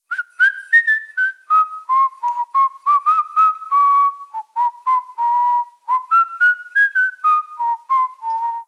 Mujer silbando 2
silbar
silbido
Sonidos: Acciones humanas
Sonidos: Voz humana